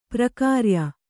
♪ prakārya